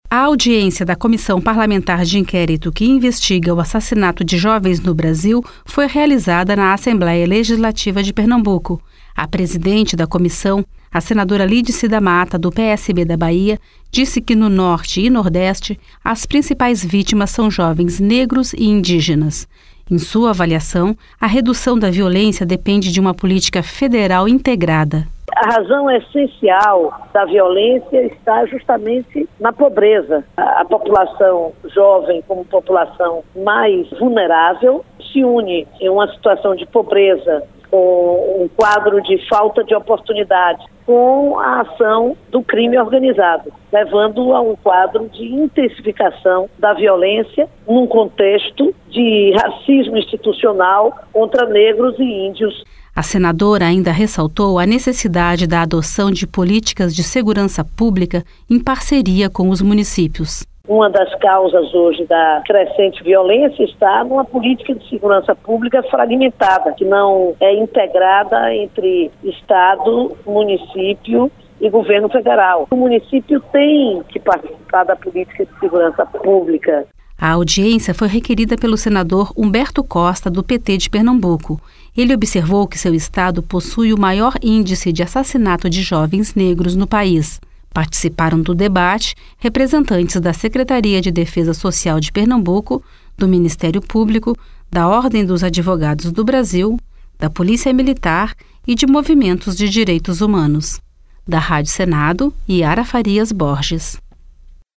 Audiência pública
Senadora Lídice da Mata